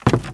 STEPS Wood, Creaky, Walk 06.wav